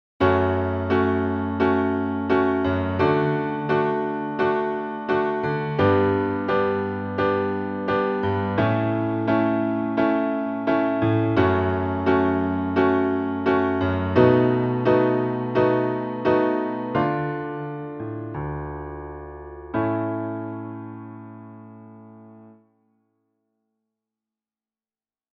Instead of the expected release on an E we get a Dm!